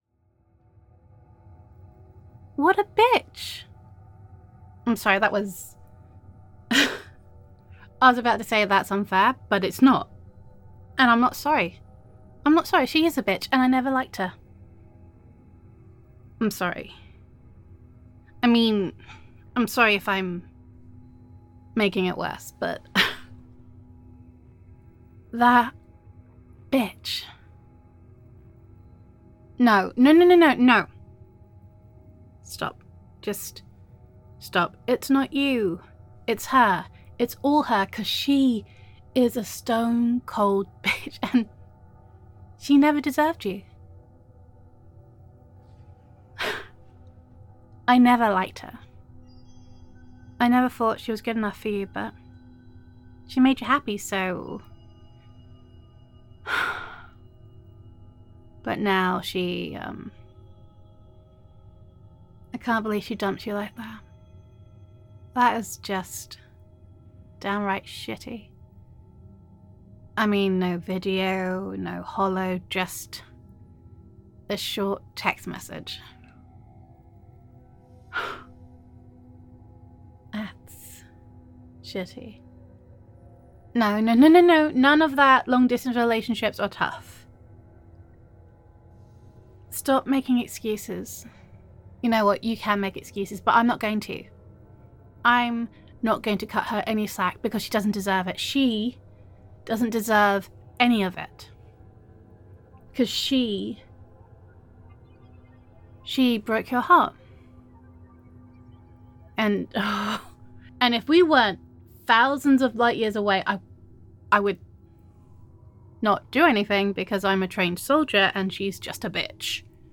[F4A] I Always Have Your Back
[Best Friend Roleplay]